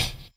Hat (OKAGA, CA).wav